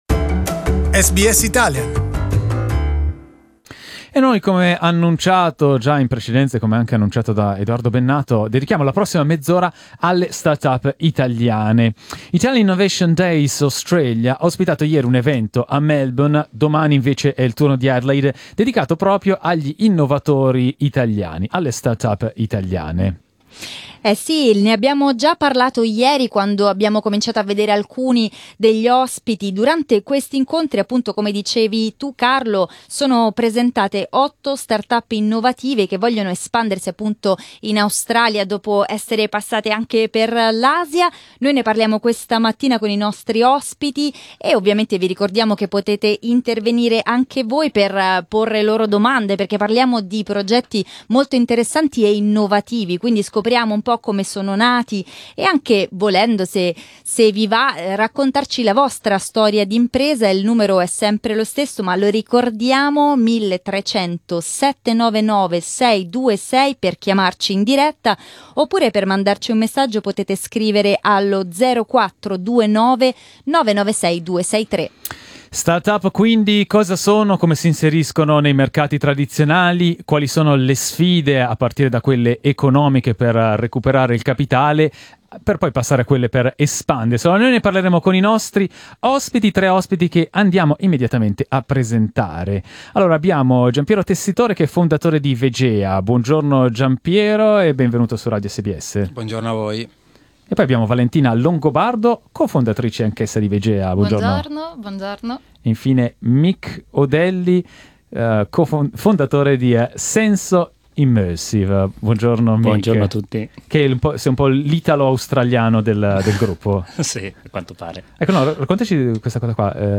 Our studio guests